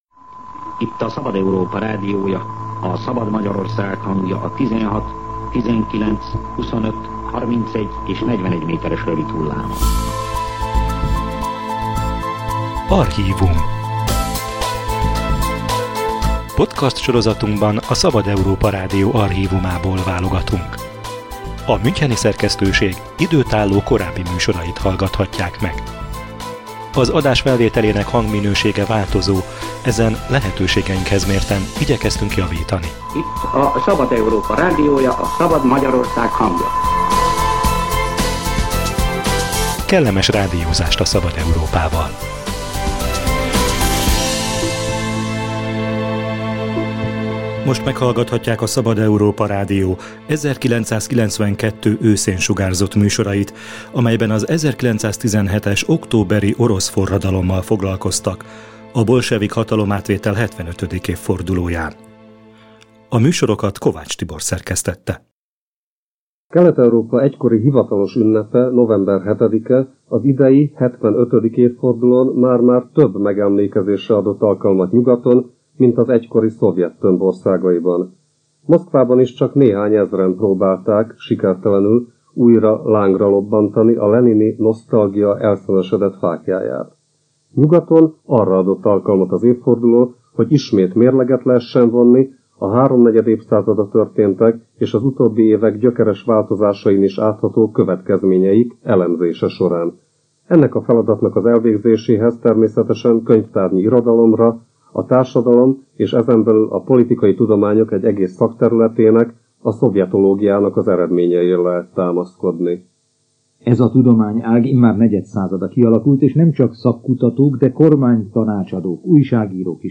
Az 1917-es októberi orosz forradalom 75. évfordulóján részletesen foglalkozott a Szabad Európa Rádió a hatalomátvétel történetével, hátterével és következményeivel. Az 1992-ben több részletben sugárzott műsort két részben tesszük közzé.